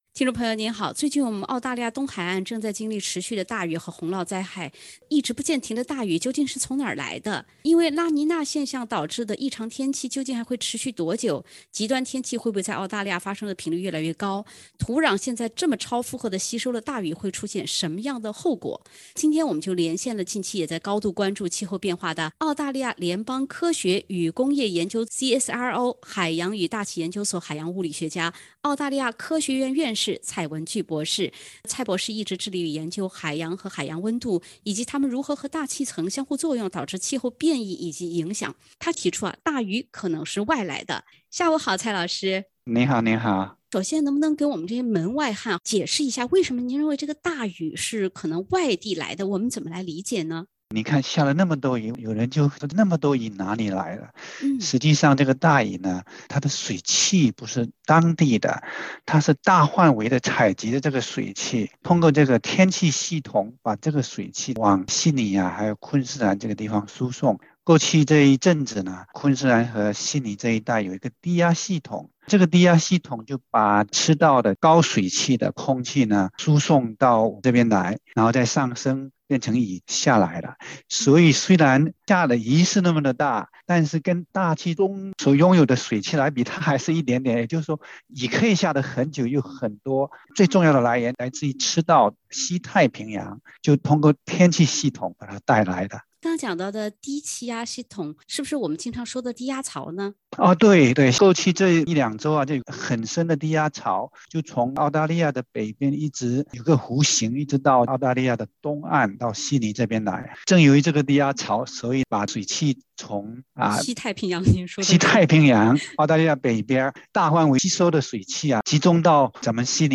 【专访】澳大利亚科学院院士：从现在到五月有更大几率有更多雨